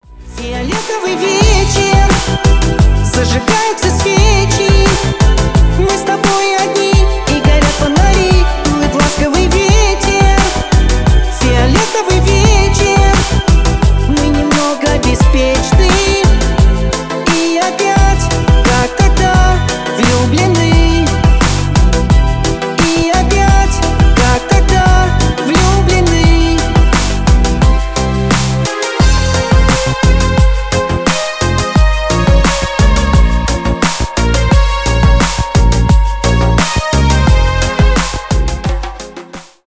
поп , романтические